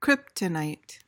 PRONUNCIATION:
(KRIP-tuh-nyt)